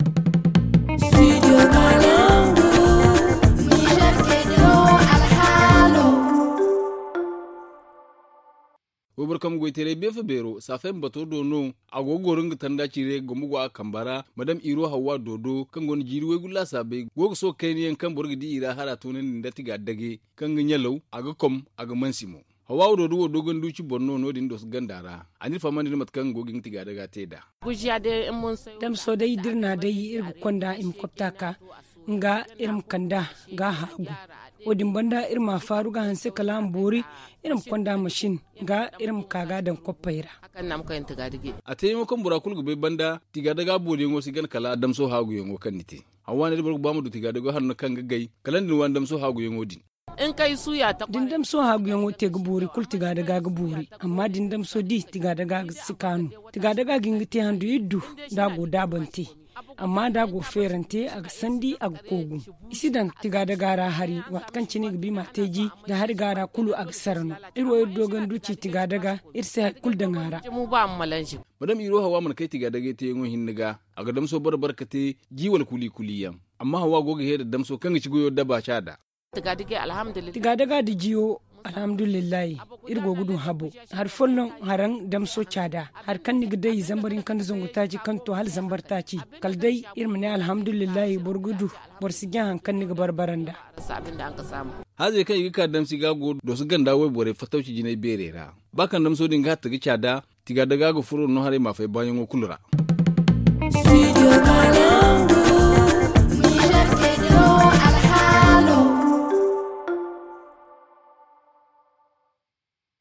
Voici son reportage.